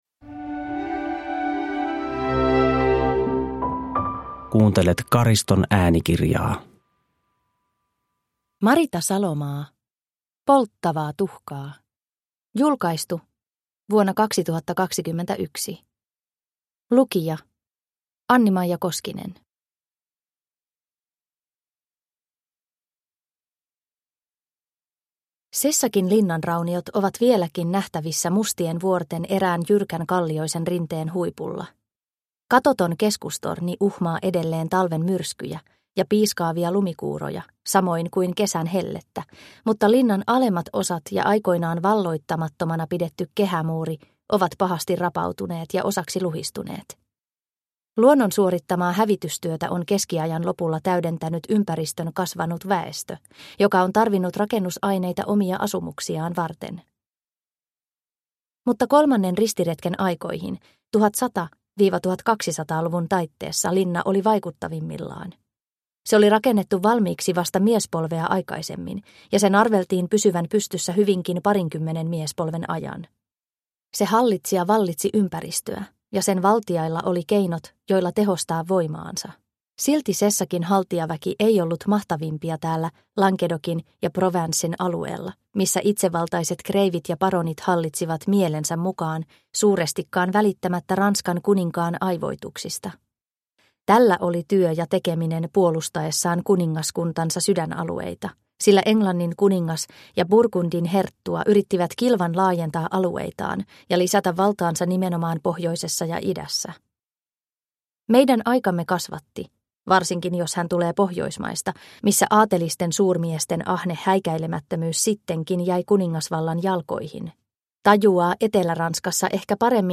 Polttavaa tuhkaa – Ljudbok – Laddas ner